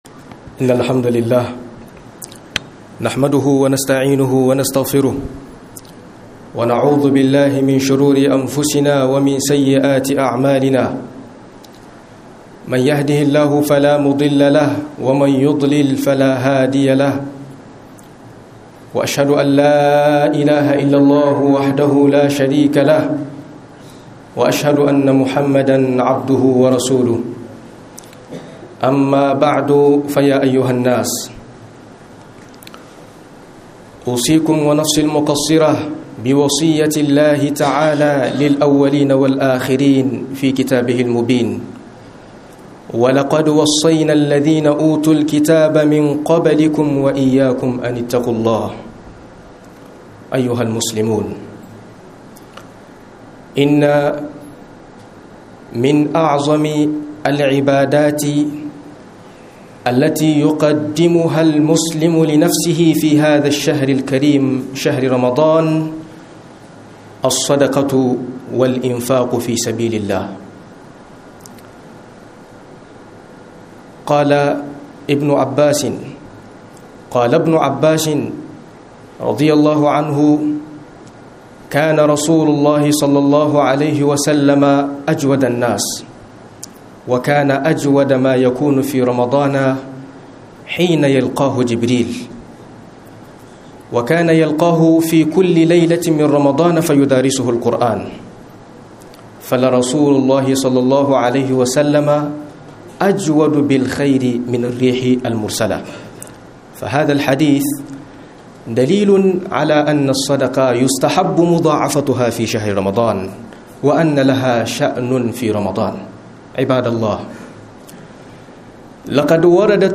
05 Kurkuran mutane game da Sadaka - HUDUBOBI